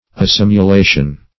Search Result for " assimulation" : The Collaborative International Dictionary of English v.0.48: Assimulation \As*sim`u*la"tion\, n. [L. assimulatio, equiv. to assimilatio.]